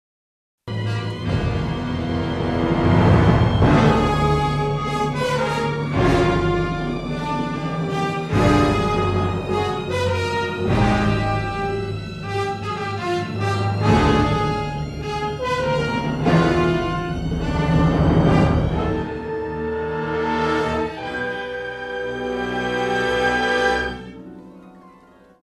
I had stuff played by real symphony orchestra in college, before DBZ...so yeah.
Before DBZ, live orchestra (sight read by students, no rehearsal)
ViolentOrch.mp3